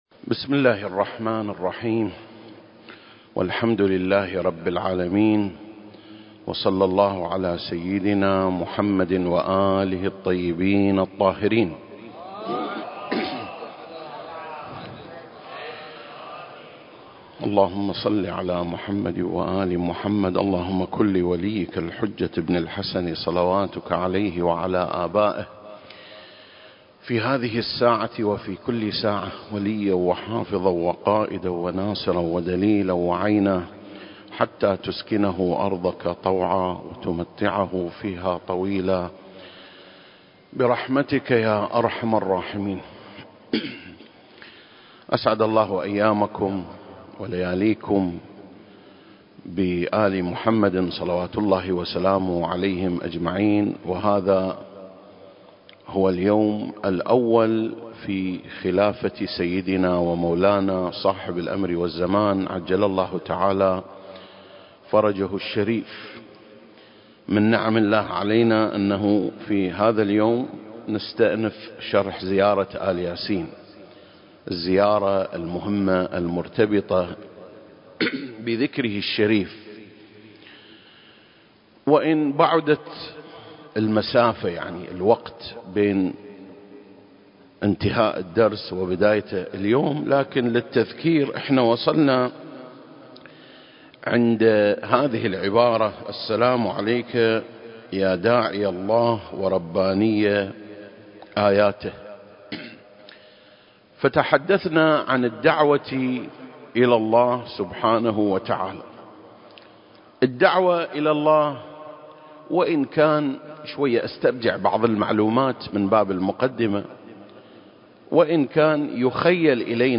سلسلة: شرح زيارة آل ياسين (25) - يا داعي الله (3) المكان: مسجد مقامس - الكويت التاريخ: 2021